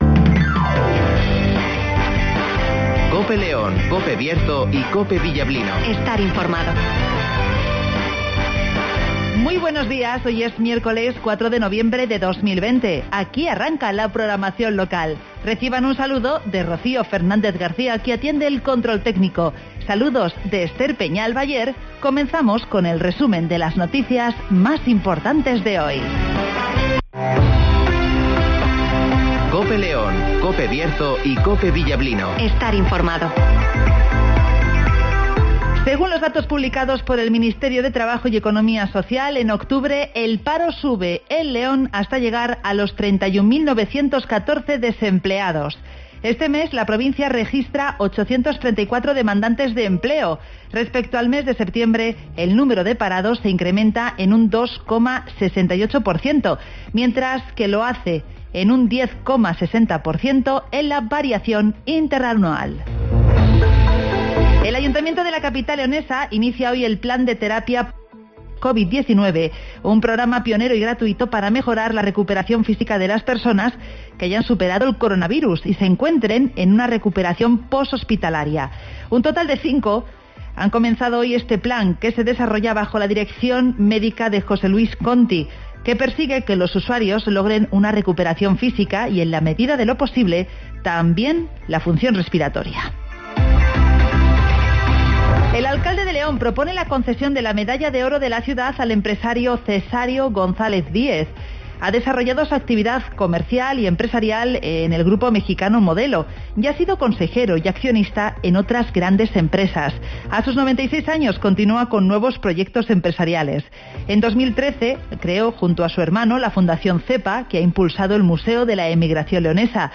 Avance informativo, El Tiempo (Neucasión) y Agenda (Carnicerias Lorpy)